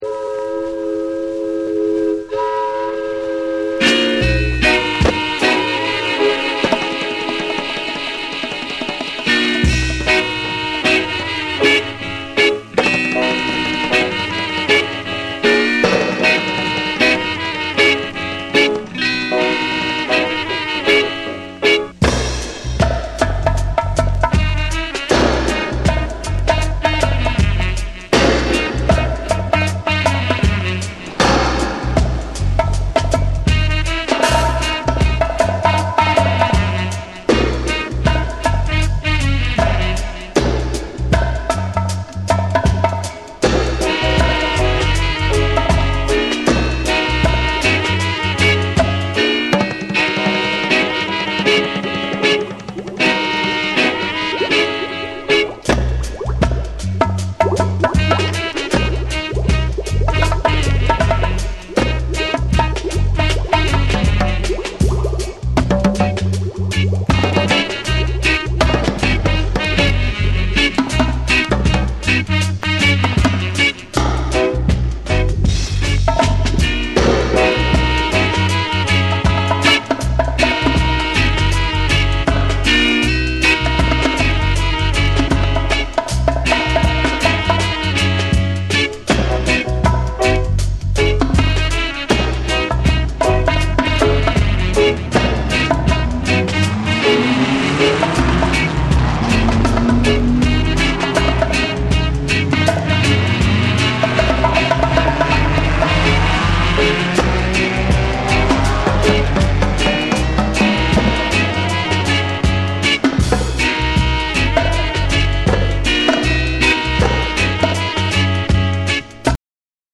重厚なベースと鋭いカッティング、ダイナミックなミキシングが際立つ70年代ミキシング・ダブの真髄を収録。